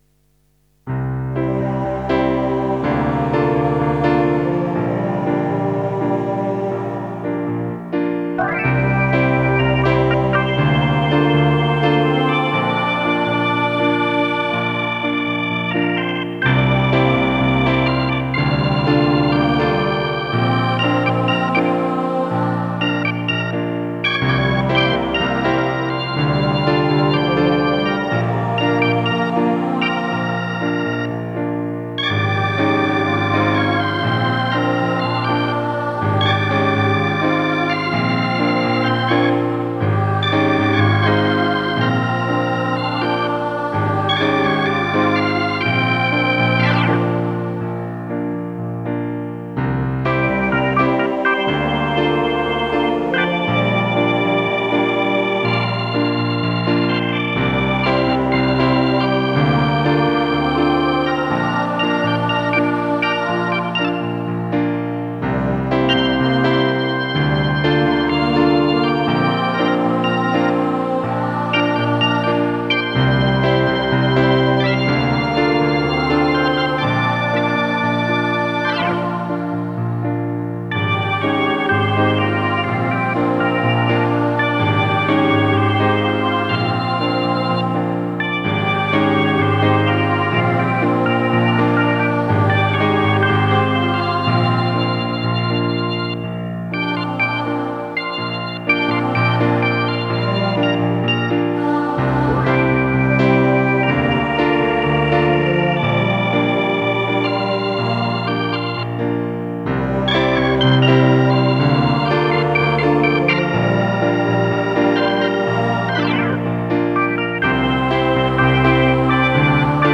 Patetitsch fröhliches Intro. Mit Piano, Orgel und Chor.